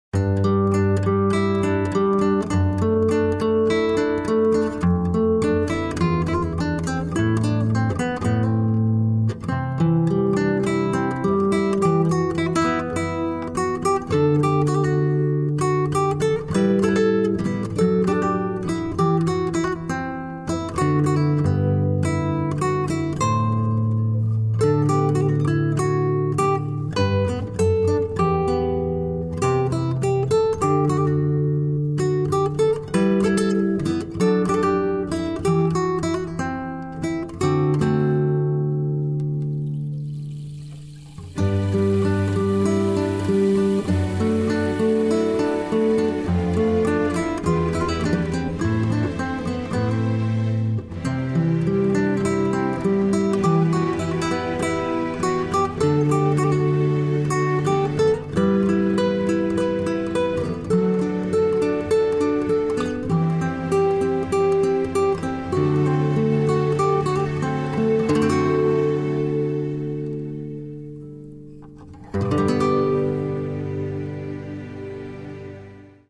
Demo grabado entre enero y abril del 2001
Voz, coros y percusión
Bajo, coros, teclados y percusión
Guitarra y coros
Primera guitarra, guitarra española y coros
Batería, teclado, percusión y coros
Saxos (tenor y alto)
saxo alto